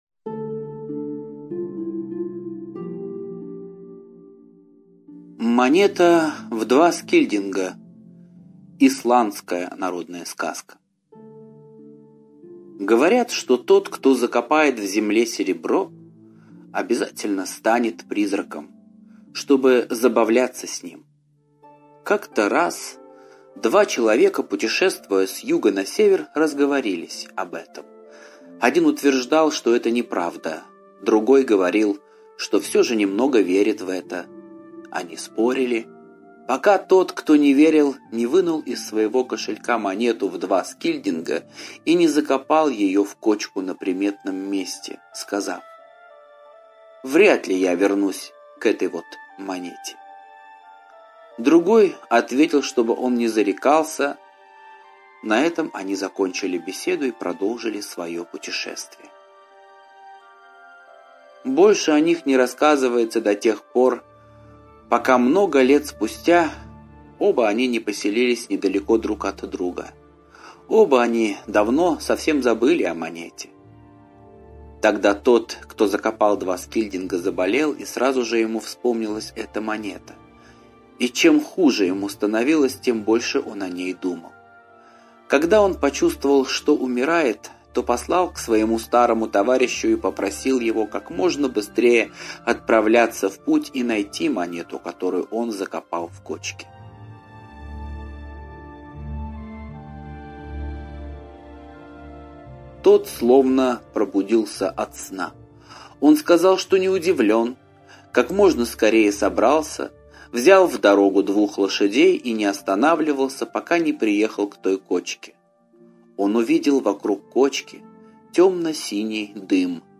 Монета в два скильдинга - исландская аудиосказка - слушать онлайн